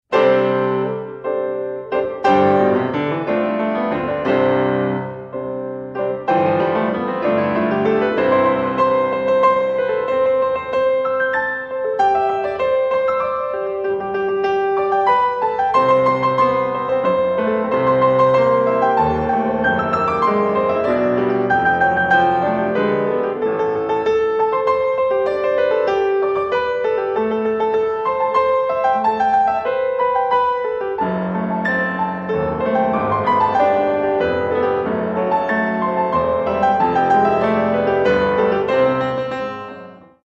Piobaireachd for solo piano